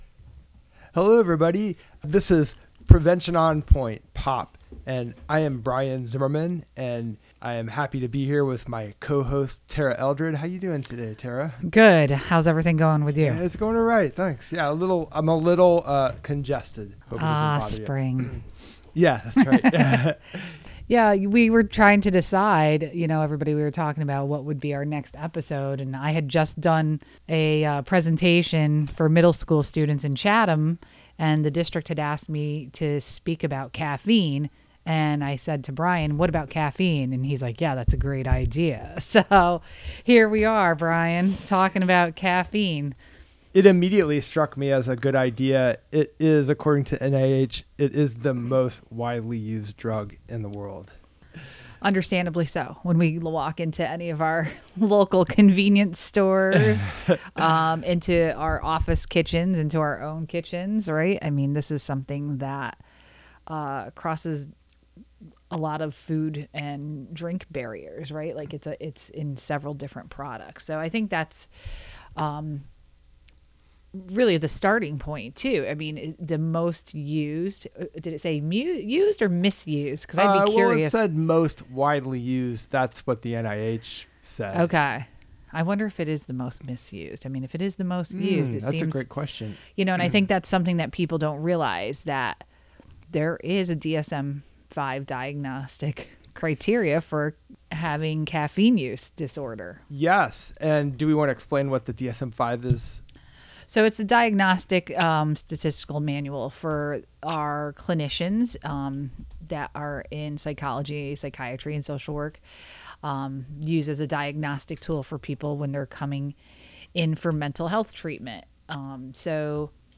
Lively conversation and useful information about substance use and misuse, prevention, wellness, and community.